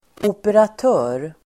Ladda ner uttalet
operatör substantiv, operator Uttal: [operat'ö:r] Böjningar: operatören, operatörer Synonymer: driftföretag Definition: person som övervakar och styr en process Sammansättningar: dataoperatör (computer operator)